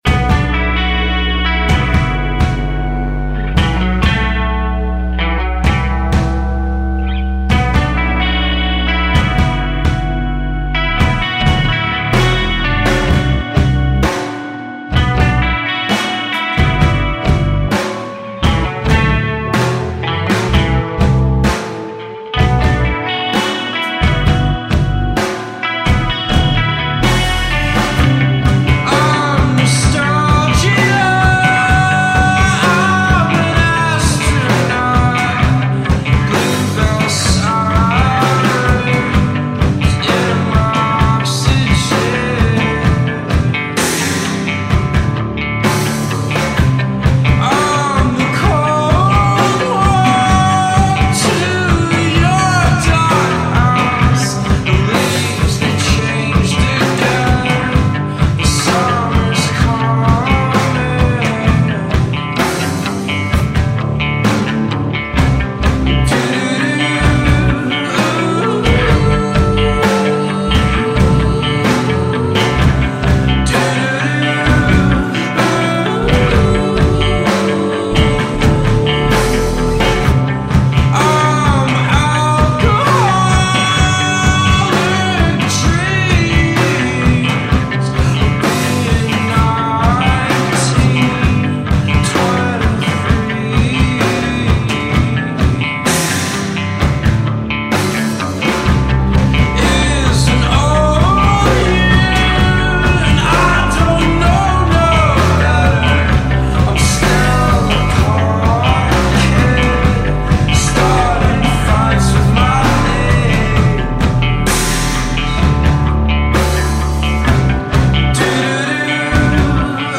distorted guitar hooks, somber synthesizers
rubbed-raw vocals